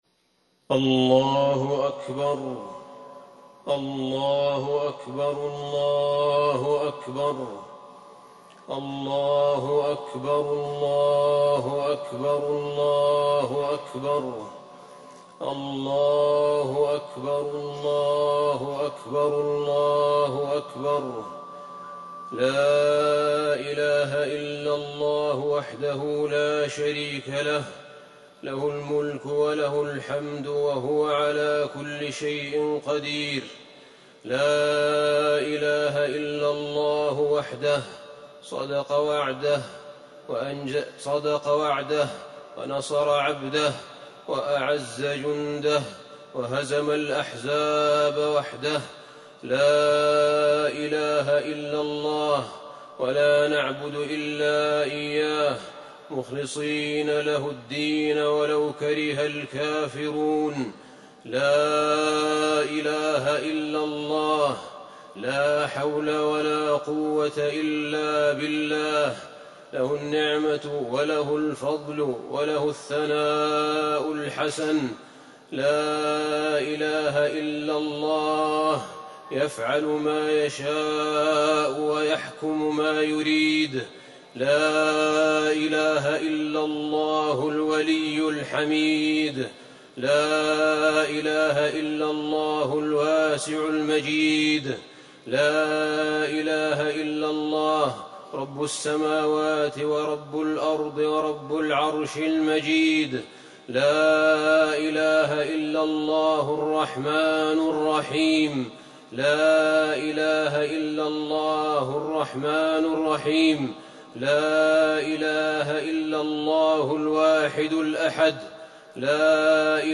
خطبة الاستسقاء - المدينة- الشيخ أحمد بن طالب
تاريخ النشر ٣ ربيع الأول ١٤٤١ هـ المكان: المسجد النبوي الشيخ: فضيلة الشيخ أحمد بن طالب بن حميد فضيلة الشيخ أحمد بن طالب بن حميد خطبة الاستسقاء - المدينة- الشيخ أحمد بن طالب